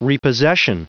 Prononciation du mot repossession en anglais (fichier audio)
Prononciation du mot : repossession